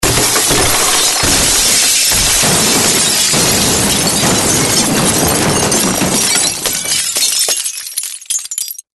Звуки мебели
Вот те на, все разбилось